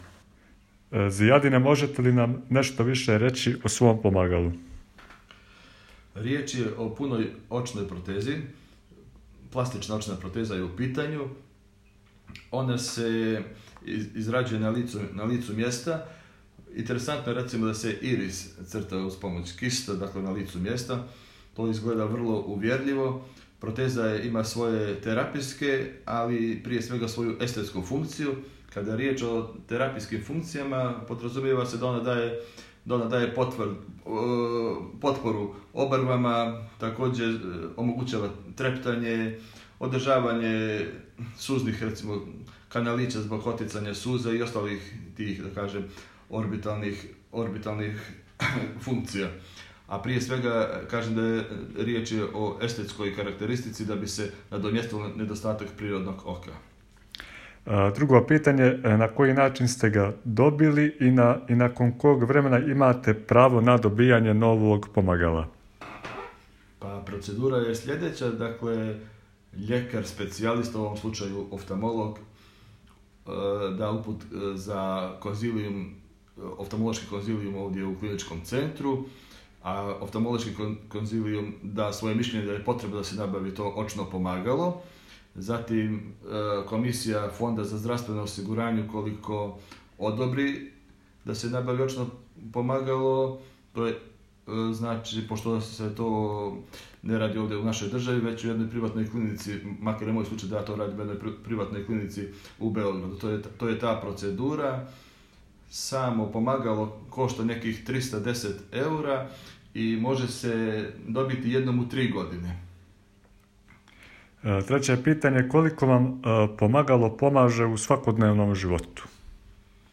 U prilogu razgovor sa korisnikom očne proteze.